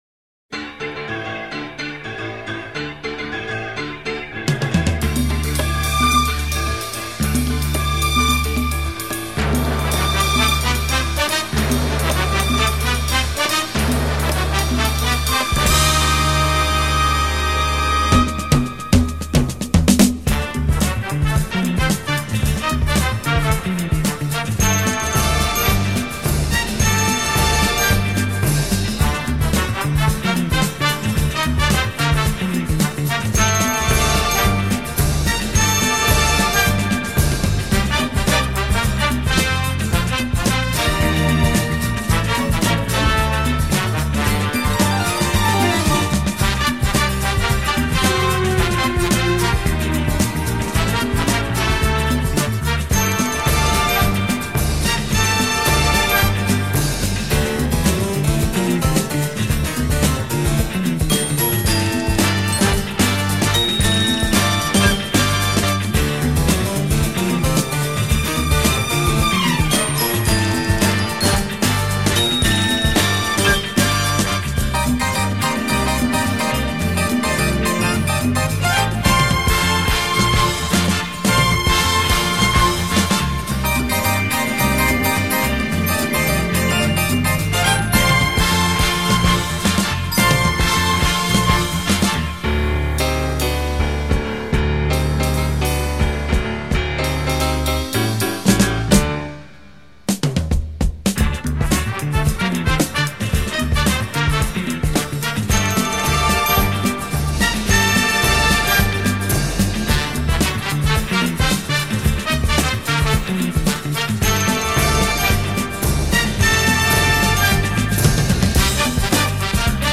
Какая жизнеутверждающая мелодия!
Действительно напоминает цирк.